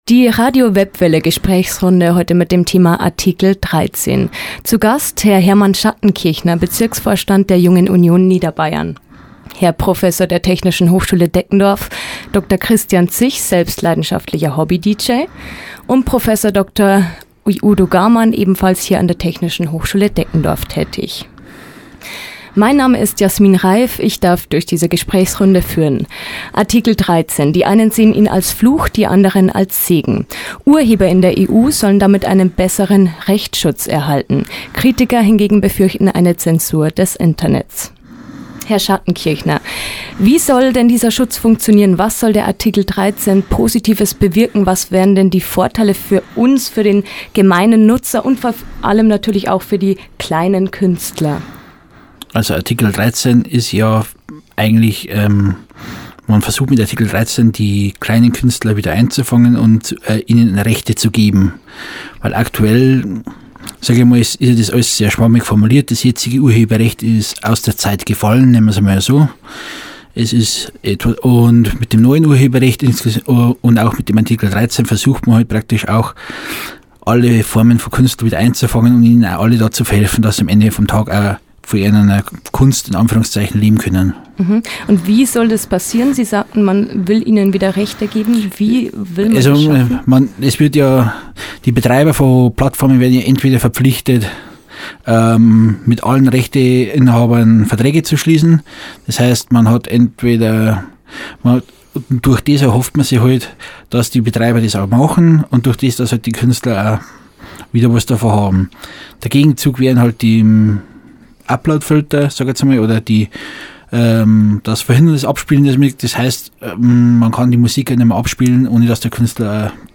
Moderation
Im Sommersemester 2019 wurde eine Diskussionsrunde über das Thema "Atikel 13" im Radiostudio der THD aufgenommen.